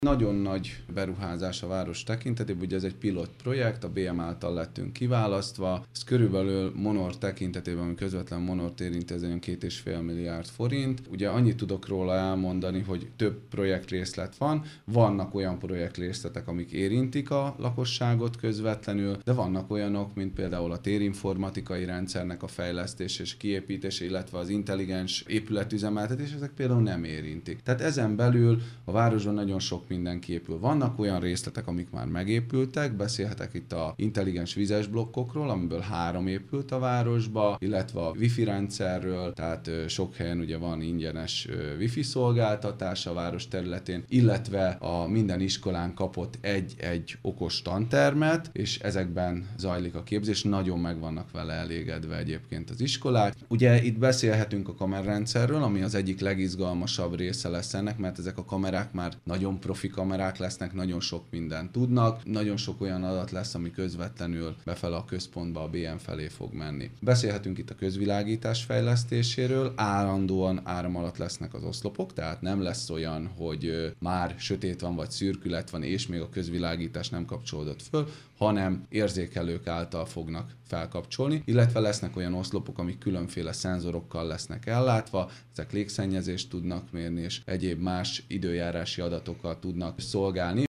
Darázsi Kálmán polgármester sorolta fel, milyen elemek tartoznak a fejlesztéshez.